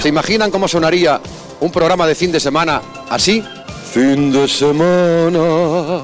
Transmissió, des de l'Hipódromo de la Zarzuela de Madrid, de la Fiesta de la Cadena SER amb motiu de l'estrena de la nova programació.